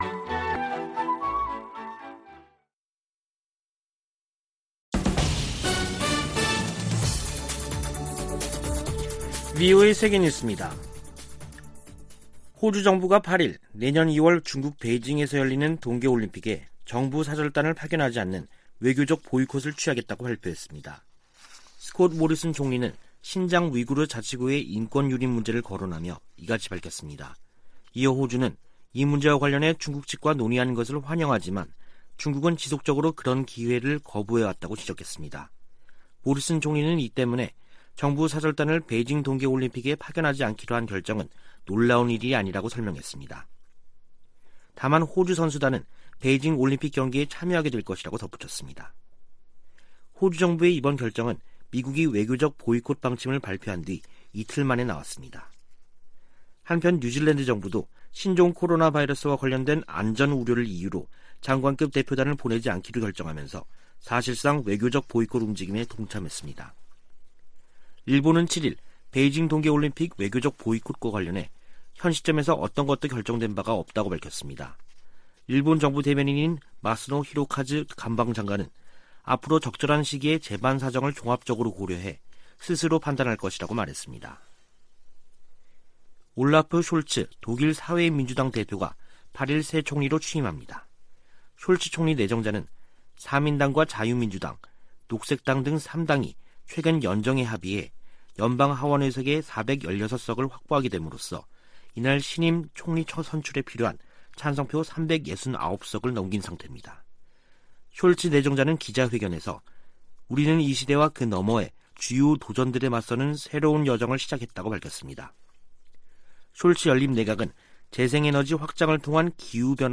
VOA 한국어 간판 뉴스 프로그램 '뉴스 투데이', 2021년 12월 8일 3부 방송입니다. 조 바이든 미국 행정부의 ‘외교적 보이콧’으로, 베이징 올림픽 무대를 활용한 한반도 종전선언의 현실화 가능성이 크게 낮아졌습니다. 올림픽 보이콧으로 북한 문제와 관련해 미-중 협력을 기대하기 어렵게 됐다고 전문가들이 지적했습니다. 문재인 한국 대통령은 종전선언에 대한 국제사회의 지지를 거듭 촉구하는 한편, 청와대는 올림픽 보이콧을 검토하지 않고 있다고 밝혔습니다.